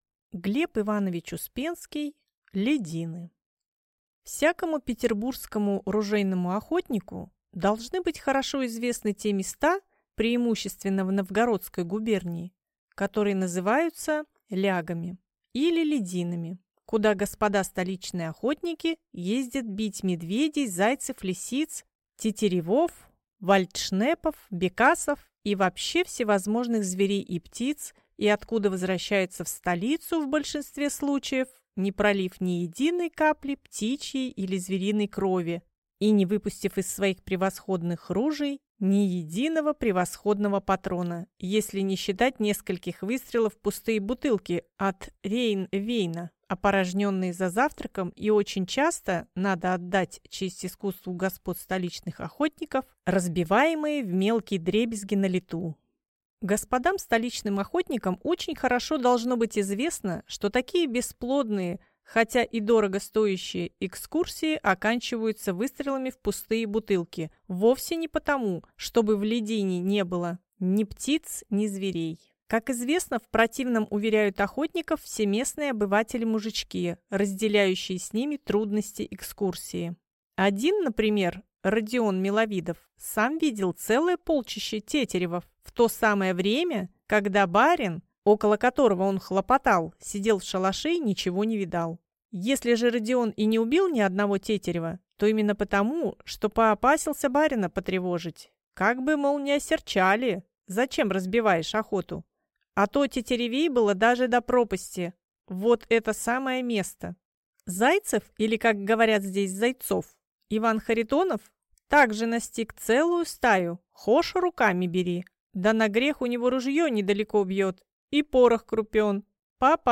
Аудиокнига «Лядины» | Библиотека аудиокниг